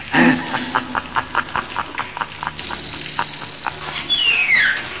Freddy laughing with his claw screeching